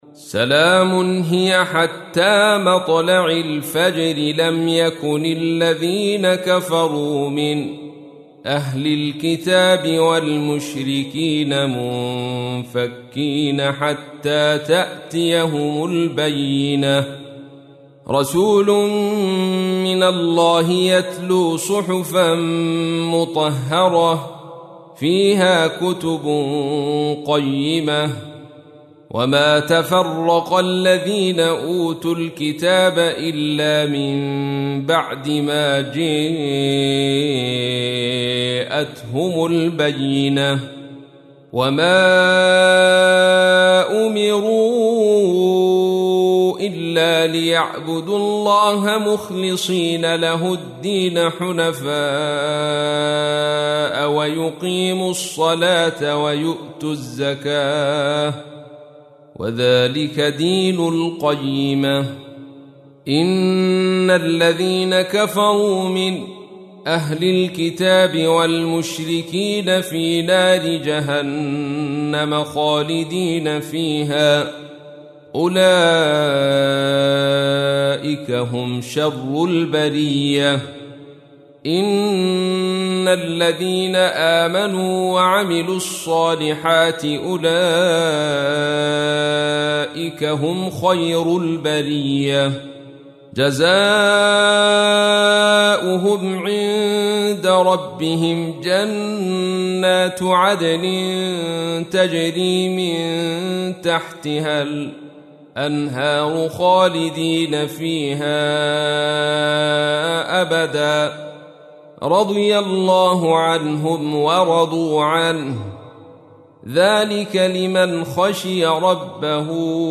تحميل : 98. سورة البينة / القارئ عبد الرشيد صوفي / القرآن الكريم / موقع يا حسين